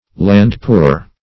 Search Result for " land-poor" : The Collaborative International Dictionary of English v.0.48: Land-poor \Land"-poor`\, a. Pecuniarily embarrassed through owning much unprofitable land.